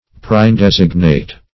Search Result for " preindesignate" : The Collaborative International Dictionary of English v.0.48: Preindesignate \Pre`in*des"ig*nate\, a. (Logic.)
preindesignate.mp3